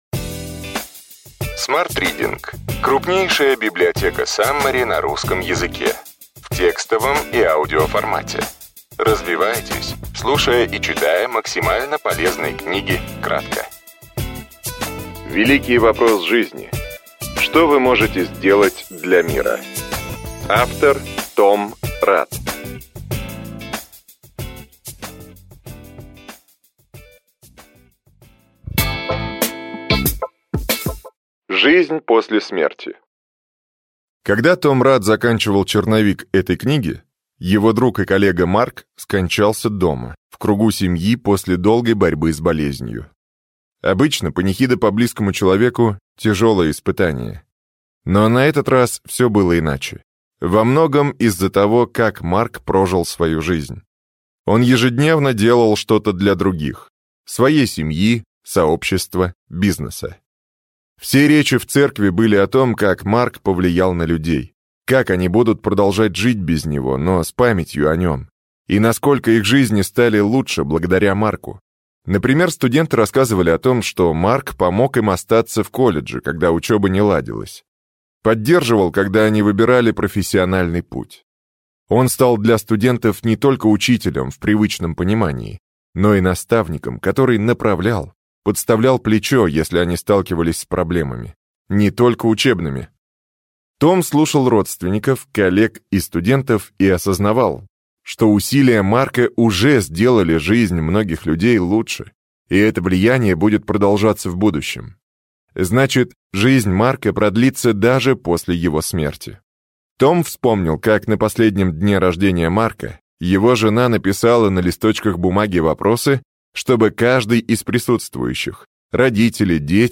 Аудиокнига Ключевые идеи книги: Великий вопрос жизни: что вы можете сделать для мира?